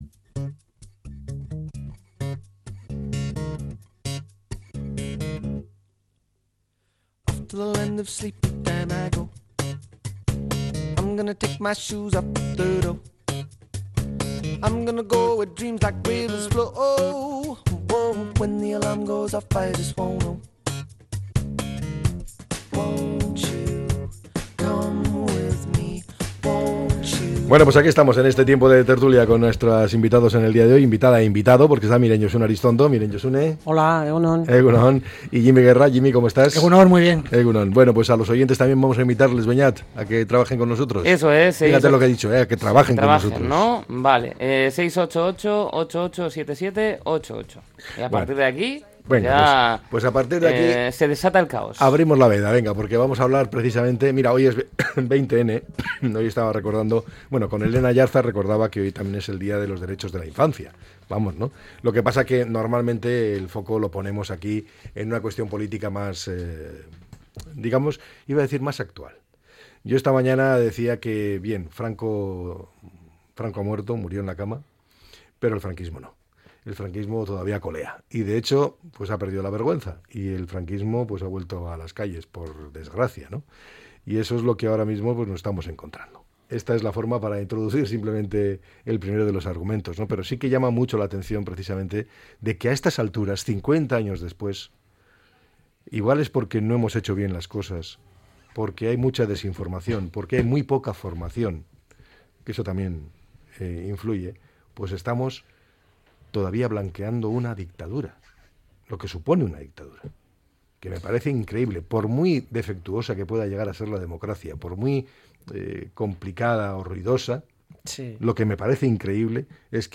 analiza a diario diferentes temas de actualidad con sus tertulianxs